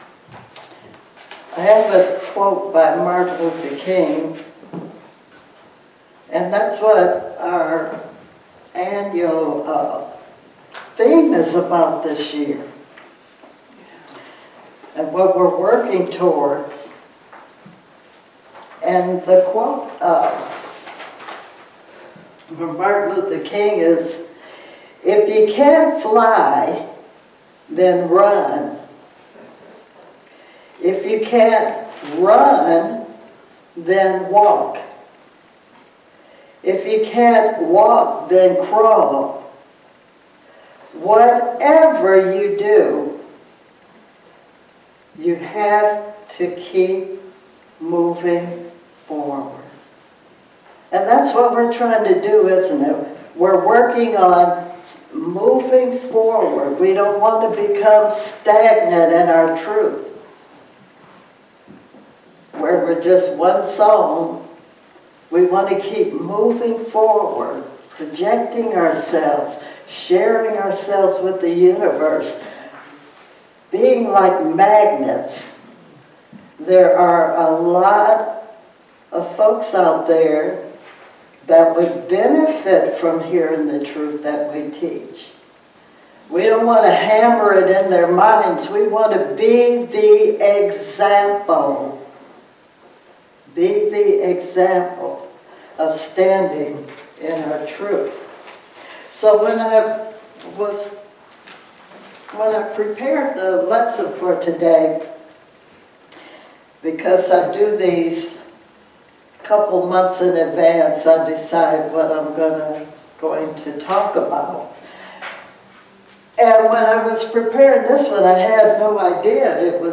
Spiritual Leader Series: Sermons 2026 Date